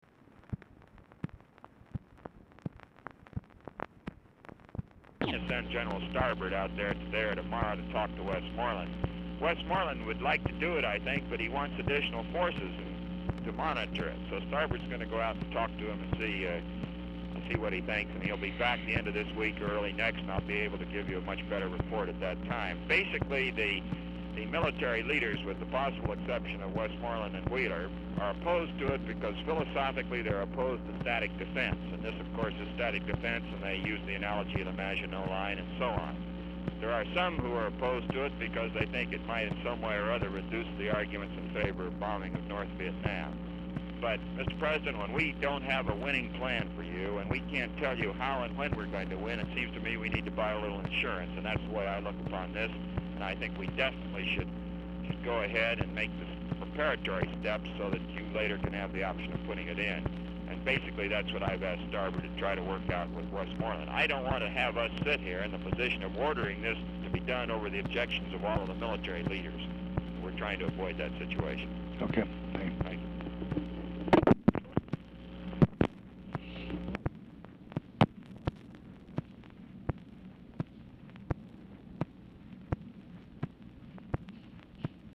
Telephone conversation # 11125, sound recording, LBJ and ROBERT MCNAMARA, 12/12/1966, 12:36PM | Discover LBJ
RECORDING STARTS AFTER CONVERSATION HAS BEGUN
Format Dictation belt
Location Of Speaker 1 Oval Office or unknown location